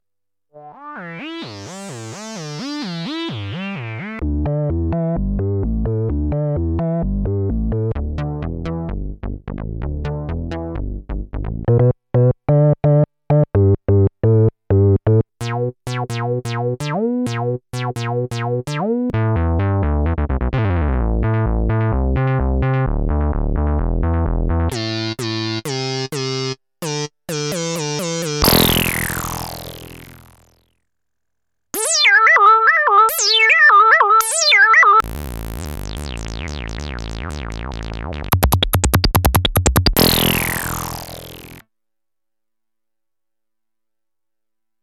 Analogue Synthesizer Module
factory song